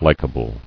[lik·a·ble]